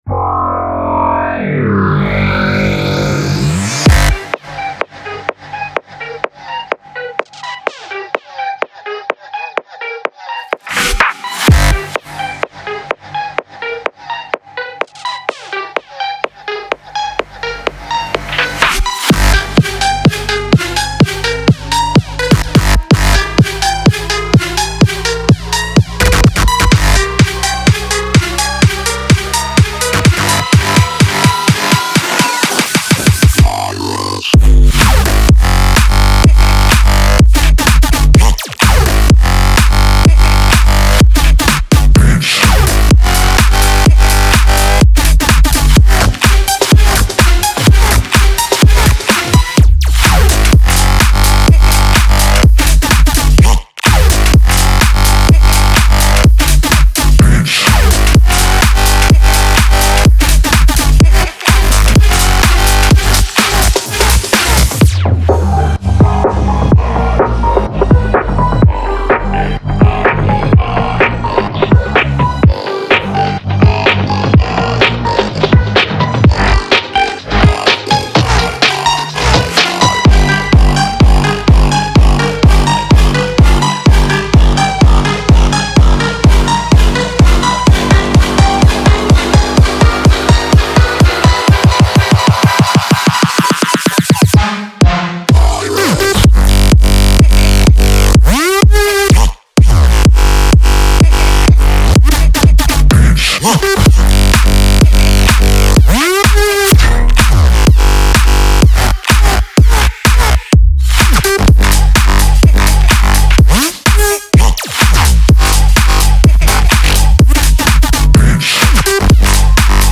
BPM126-126
Audio QualityPerfect (High Quality)
Bass House song for StepMania, ITGmania, Project Outfox